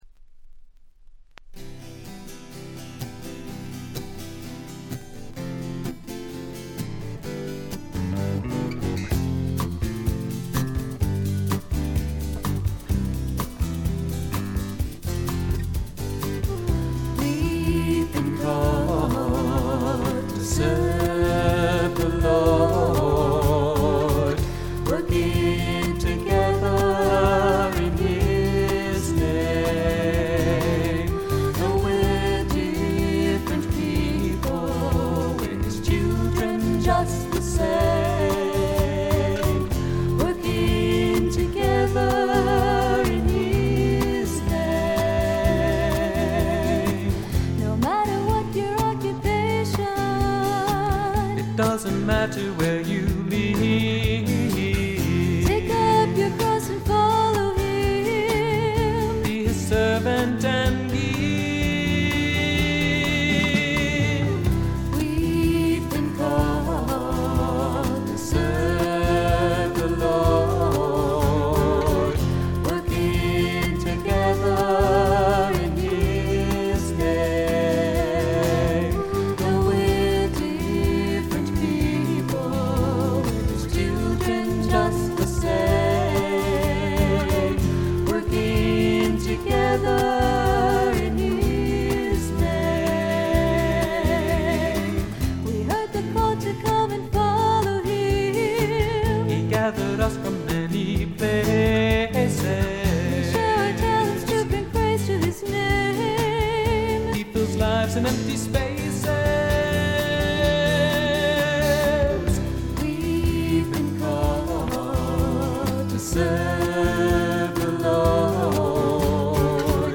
ほとんどノイズ感無し。
全体に涼やかでメロウな味わいがたいへんに美味なもので、この音で好事家達が見逃すわけがありませんね。
試聴曲は現品からの取り込み音源です。